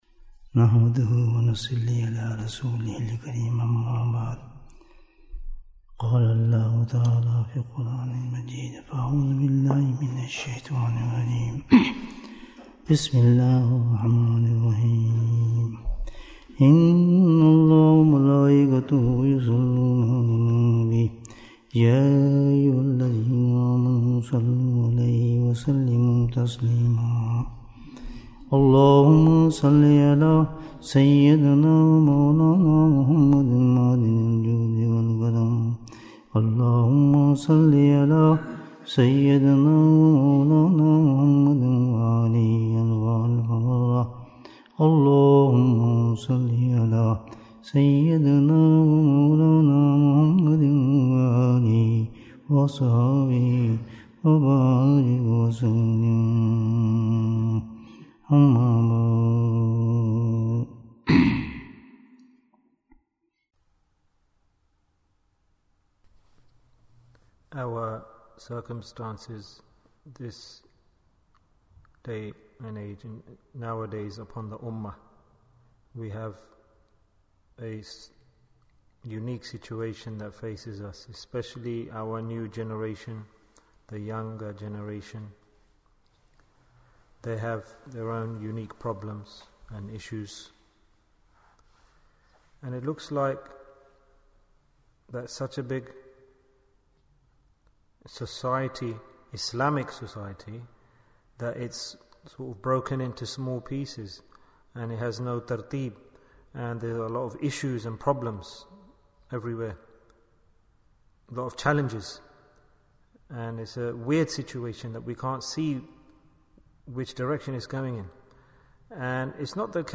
The Reality of Salaah Bayan, 62 minutes12th January, 2023